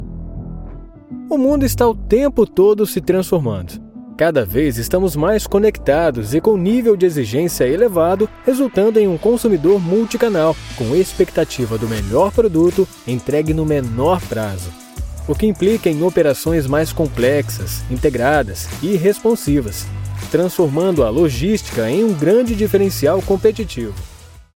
All voiceovers are recorded in an acoustic booth, resulting in clean audio free of any type of interference.
Sprechprobe: eLearning (Muttersprache):